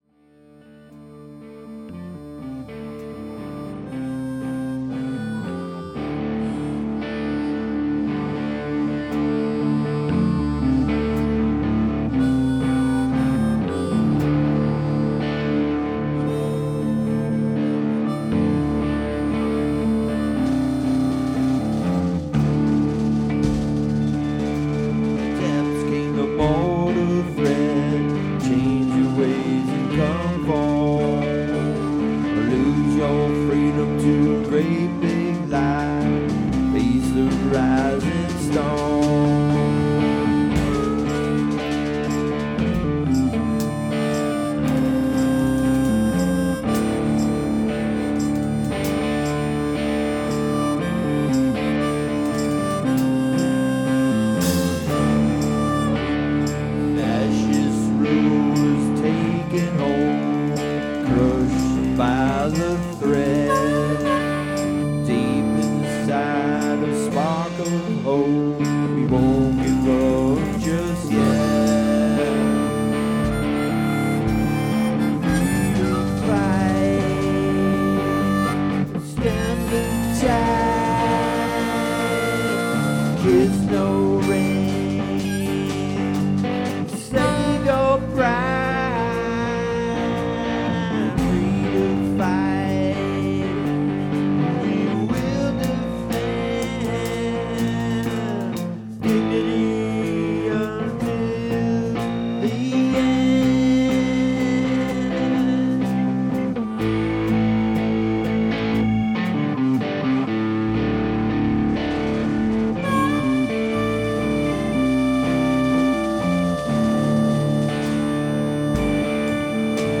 This is my fight song with an optimistic outlook.  The accompanying musicians are all from Half Moon Bay, CA, and I’m grateful they sat in.